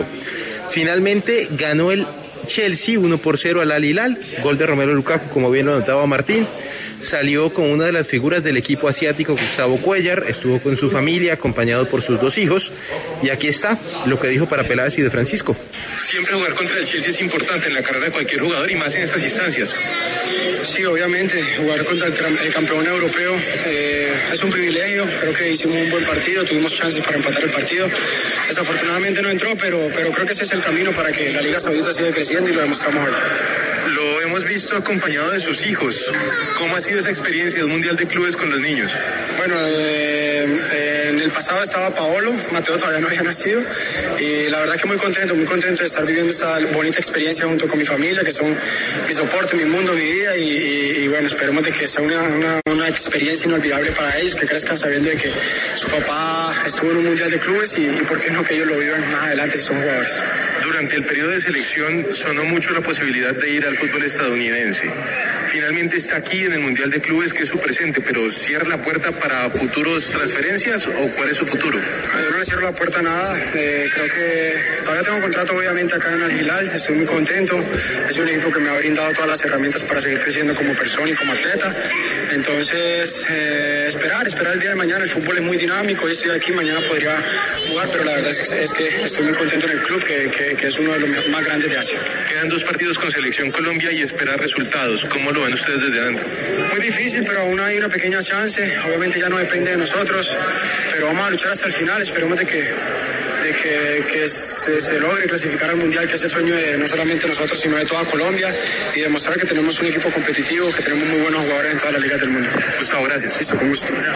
El futbolista colombiano Gustavo Cuéllar habló en los micrófonos de Peláez y De Francisco en La W sobre su actuación en el Mundial de Clubes con su equipo, el Al Hilal de Arabia Saudita, que cayó ante el Chelsea en la semifinal del torneo.